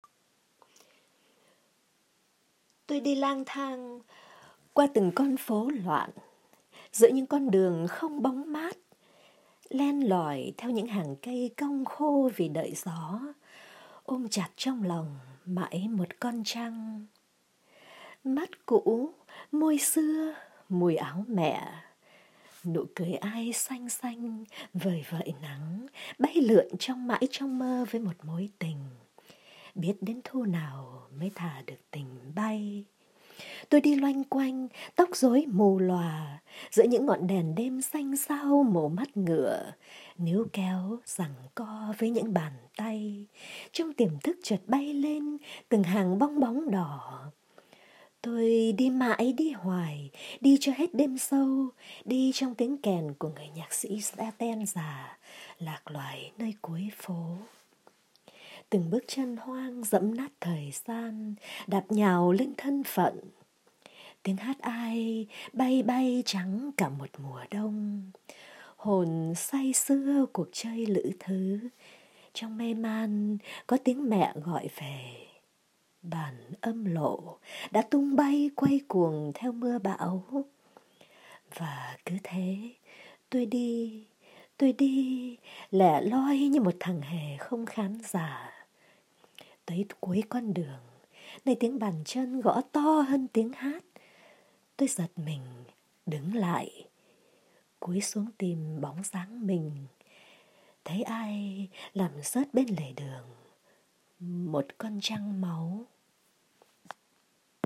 đọc thơ